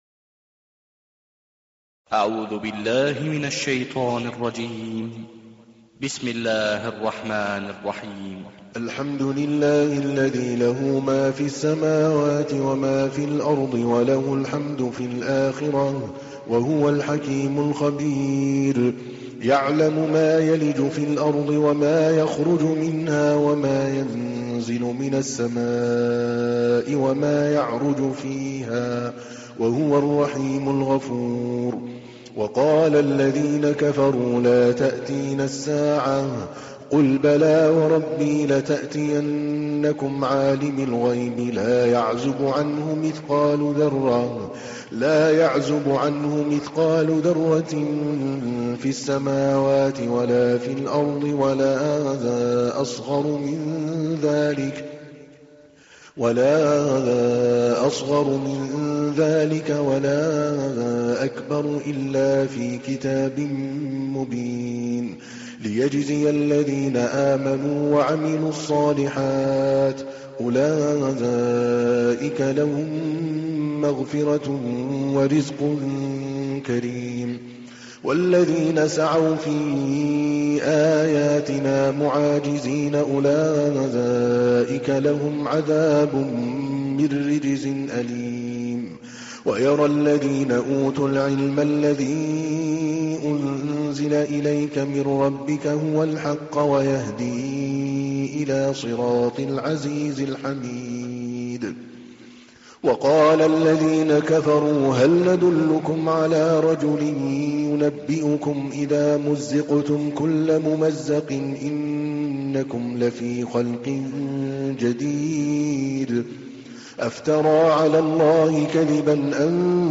تحميل : 34. سورة سبأ / القارئ عادل الكلباني / القرآن الكريم / موقع يا حسين